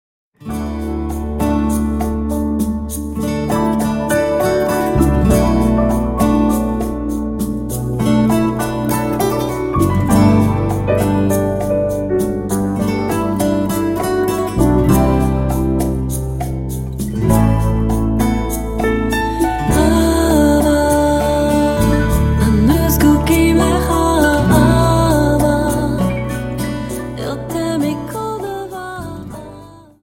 Dance: Rumba 25